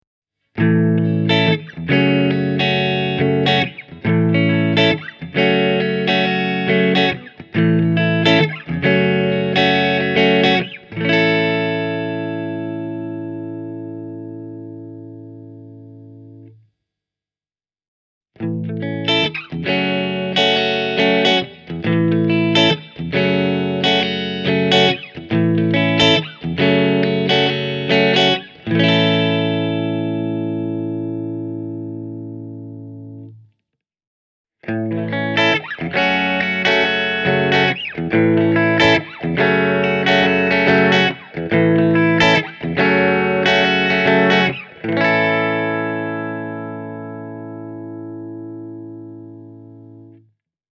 Puhtaan kanavan nimi on α – siis Alpha.
Tällaisia soundeja sain itse Bogner Goldfinger -kombosta irti:
Alpha (Post Bright on) – Hamer Studio Custom
alpha-with-post-bright-e28093-hamer-studio-custom.mp3